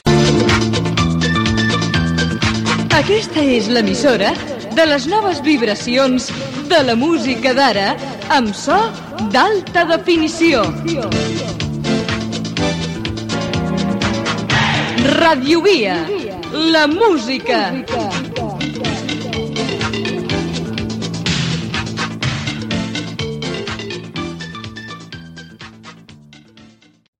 Indicatiu de l'emissora: Aquesta és l'emissora de les noves vibracions de la música d'ara amb so d'alta definició.
FM